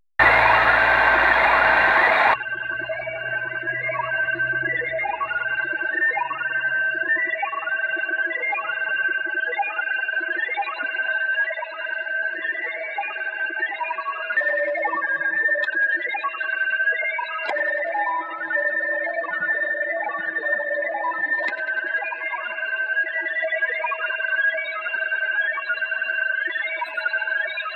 01_DNA-similar signal_(with NR)_1420kHz
Начало » Записи » Радиоcигналы на опознание и анализ
1420ssb_noise_reduction.wav